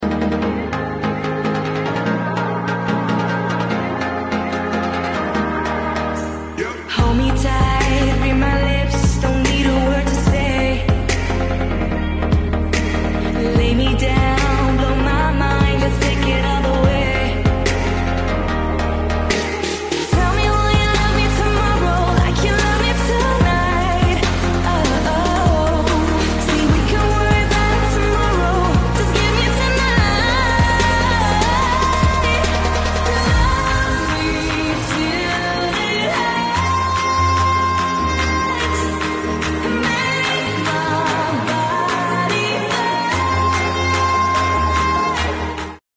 Busy streets, and endless energy